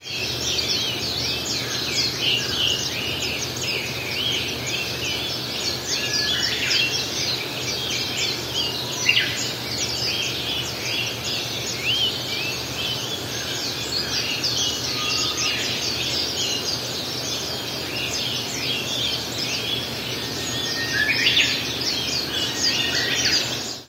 Just before sunrise here in the city of Tongyeong, a slight ruckus grows into a wild cacophony. Gulls cry as they head for the nearby port. Herons flap and squawk above them, on their way to the rice paddies over the hill.
Wonhang Morning Birds at 5:30am, Apr 19.mp3
Then from some unseen place, a Japanese Bush Warbler sings.
Somehow, they sing here, sending that long forte-piano-crescendo song across the water and through the hills that cradle this neighborhood.
Wonhang-Birds-Morning-Apr-19.mp3